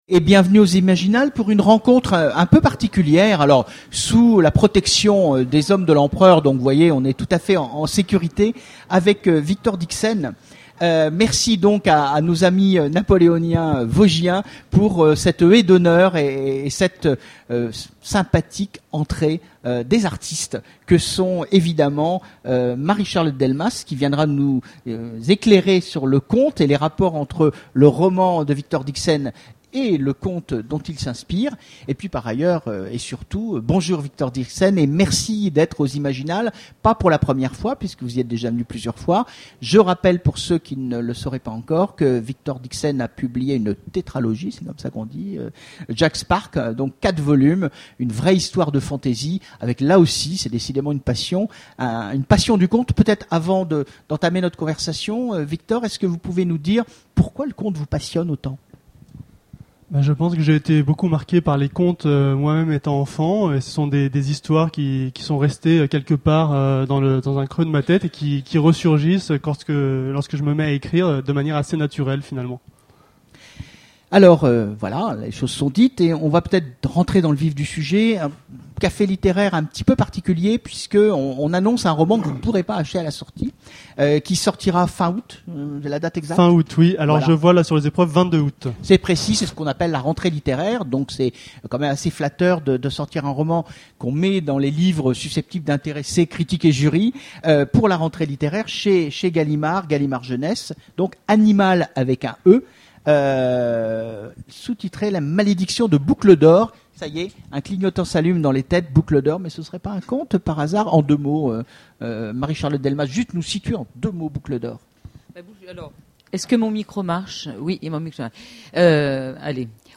Imaginales 2013 : Conférence Un roman post-napoléonien